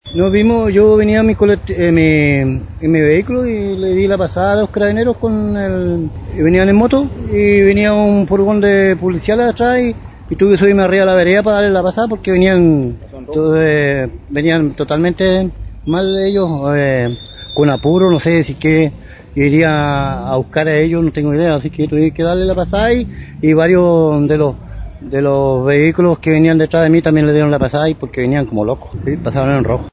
Esta situación fue relatada por un hombre que circulaba por el lugar, quien sostuvo que se debió subir a la vereda por la velocidad con la que circulaba un móvil de Carabineros, que habría pasado con luz roja.
testigo-accid.mp3